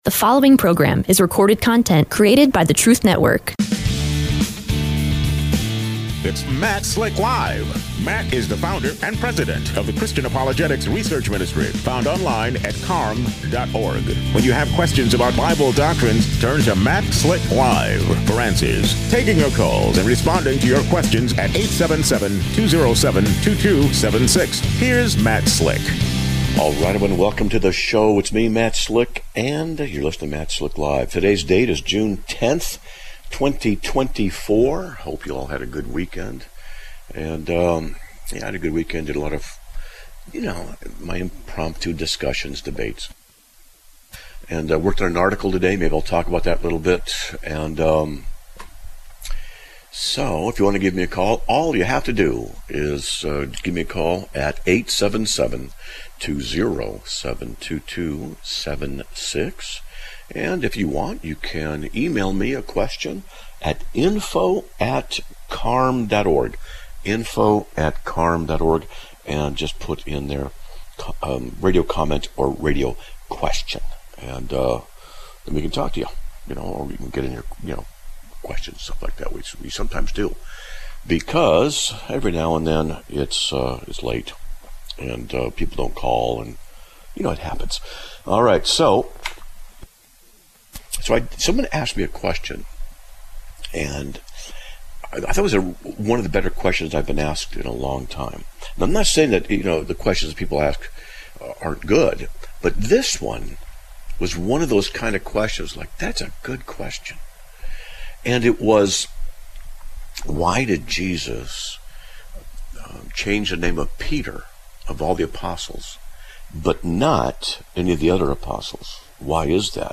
Live Broadcast